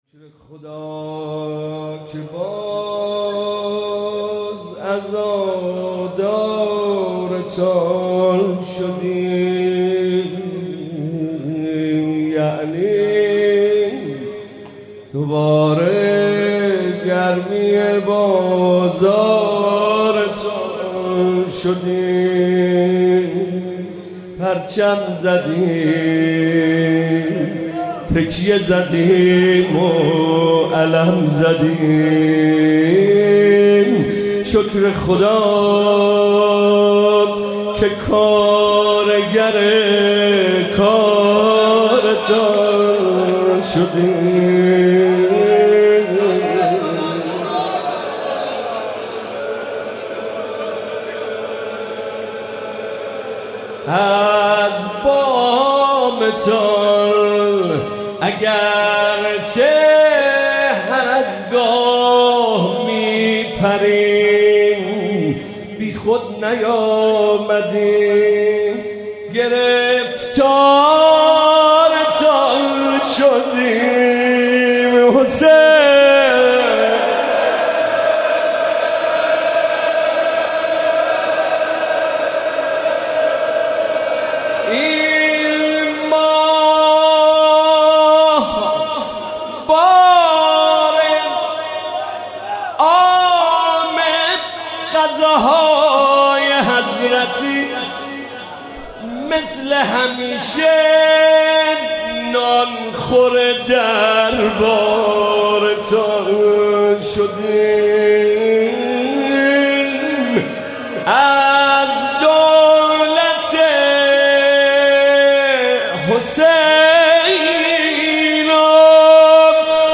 🔹هیئت مکتب الزهرا(س)